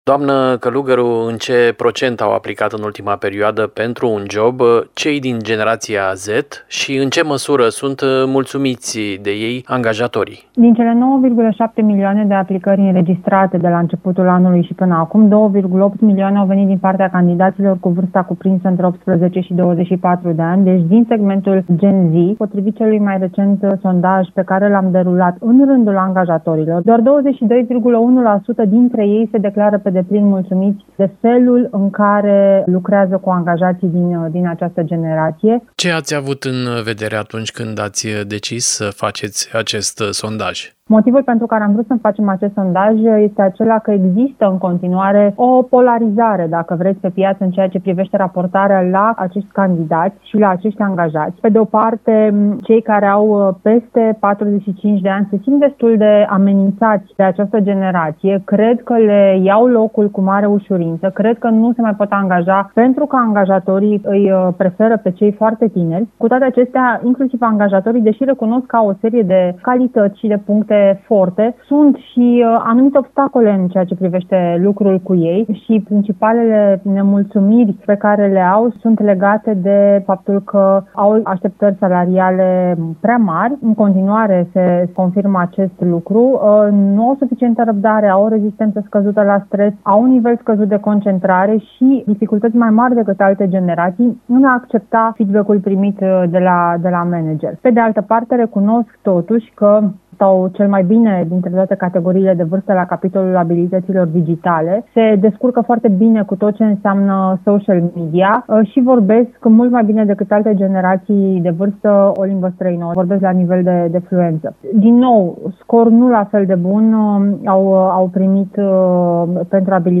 a discutat subiectul, cu expertul în comunicare